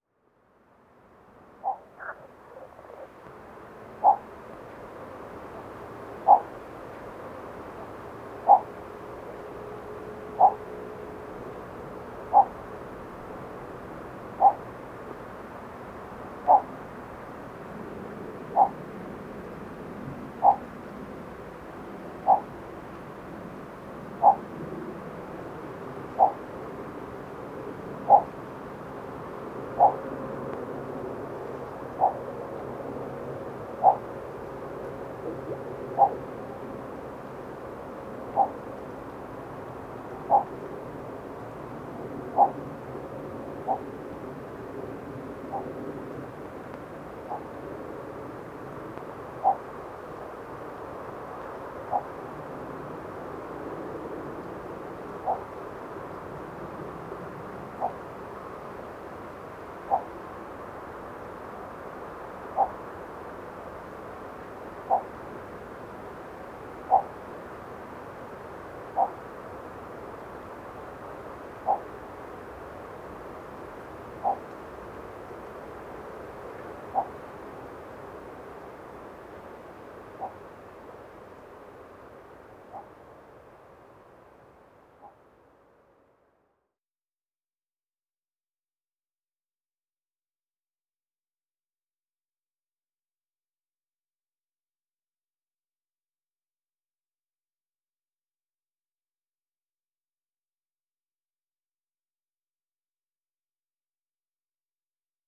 Garza pequena
Ixobrychus minutus
Canto